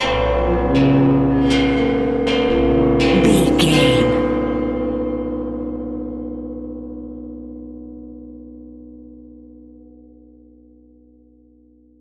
Low Horror Bells.
Atonal
ominous
haunting
eerie
synth
ambience
pads